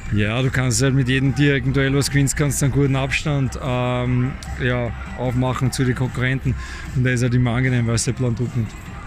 Stimmen: Wolfsberger AC vs. SK Sturm Graz